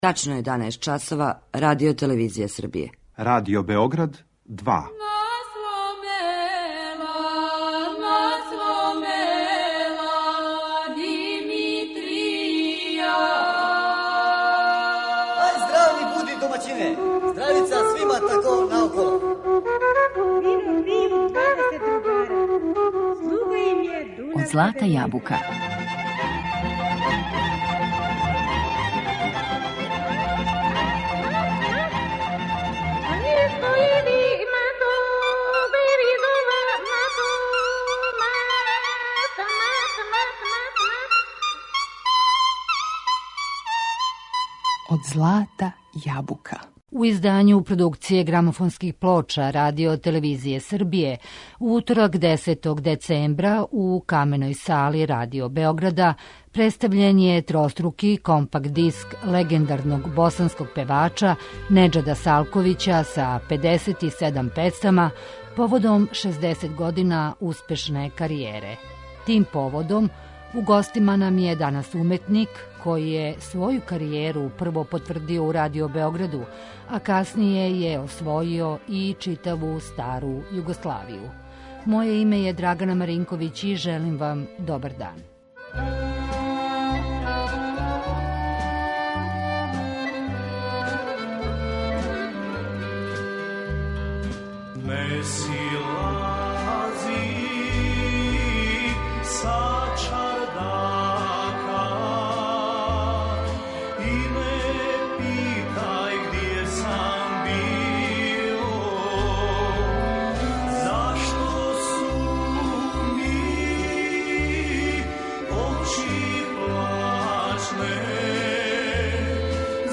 О песмама, музици, каријери и концертима за данашњу емисију Од злата јабука, говори нам уметник Неџад Салковић.